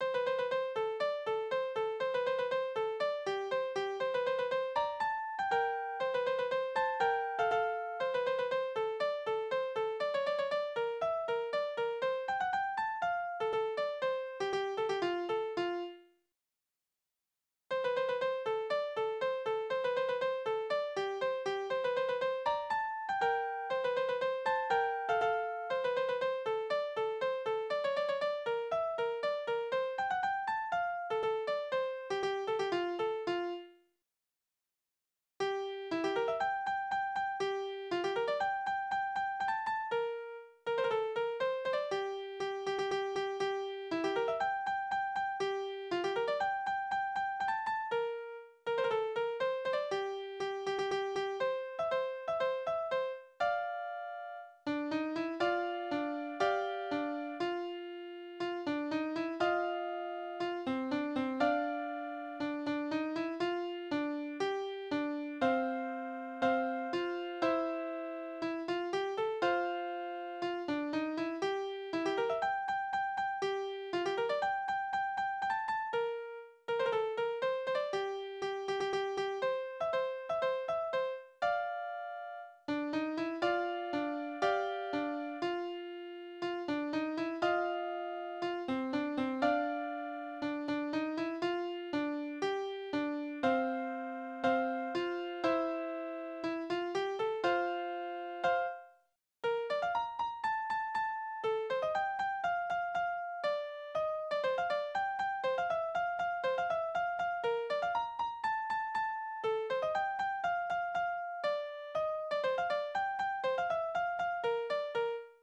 Tonart: F-Dur, B-Dur
Taktart: 2/4
Tonumfang: kleine Septime über eine Oktave
Besetzung: vokal
Anmerkung: Das Stück besteht aus zwei Teile, wobei der zweite Teil ein Trio ist. Das Stück ist teiweise zwei- oder dreistimmig